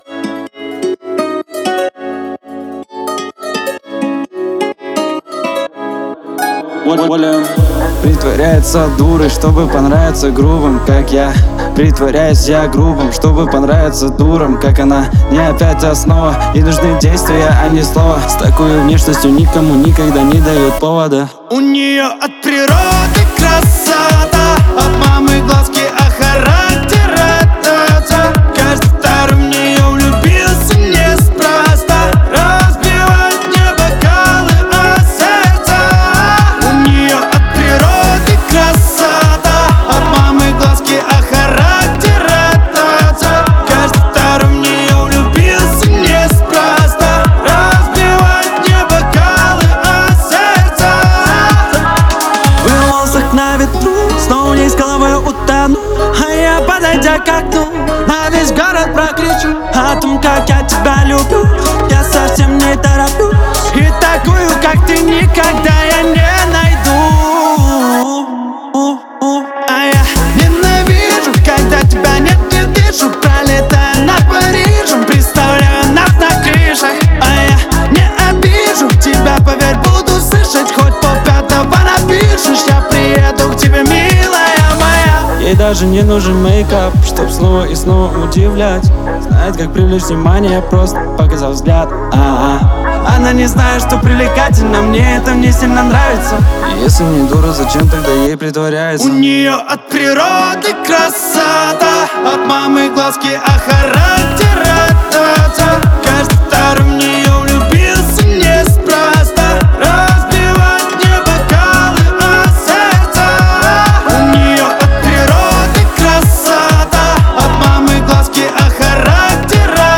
танцевальные песни , русские песни